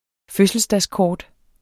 Udtale [ ˈføsəlsdas- ]